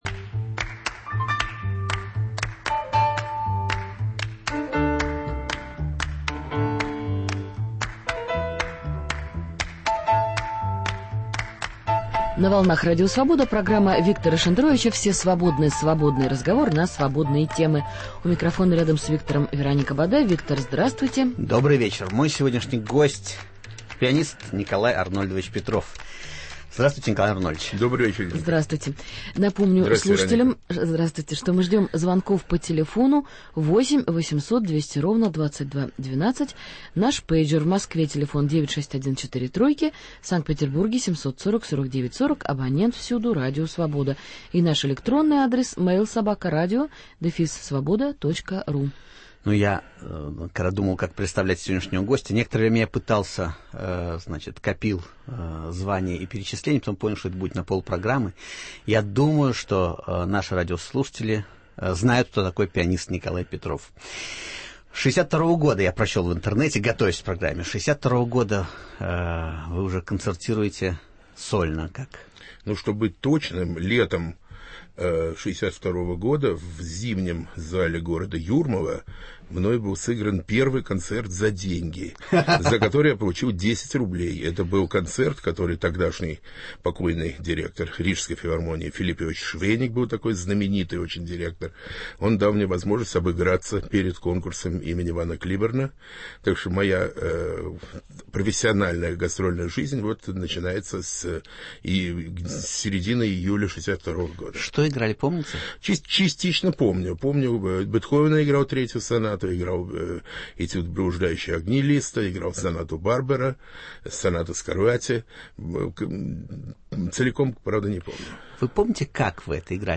В гостях у Виктора Шендеровича - выдающийся российский пианист, исполнитель-виртуоз, народный артист СССР, лауреат Государственной премии Росси, президент Академии российского искусства, профессор Московской консерватории Николай Петров.